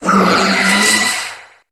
Cri de Zeraora dans Pokémon HOME.